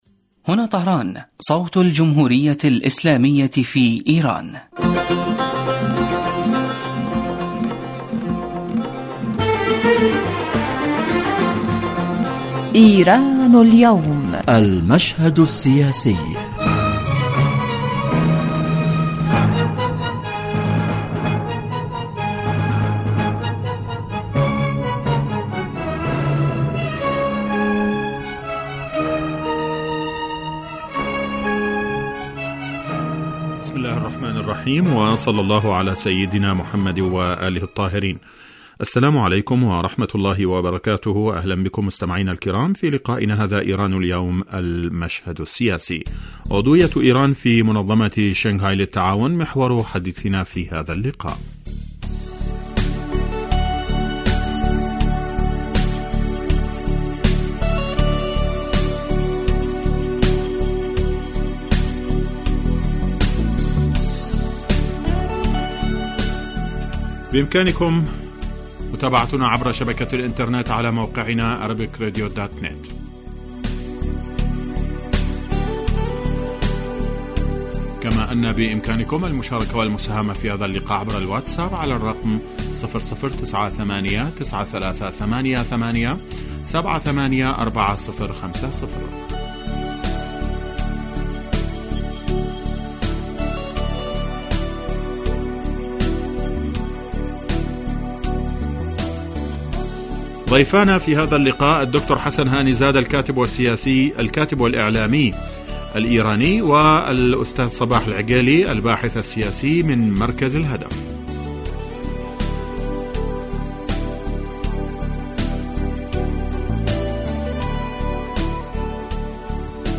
يتناول هذا البرنامج كما هو واضح من تسميته آخر القضايا والأحداث الإيرانية ويختص كل أسبوع بموضوع من أهم موضوعات الساعة في ايران وتأثيره على الساحة الإقليمية ويتطرق إليه ضيف البرنامج في الاستوديو كما يطرح نفس الموضوع للمناقشة وتبادل النظر على خبير آخر يتم استقباله على الهاتف.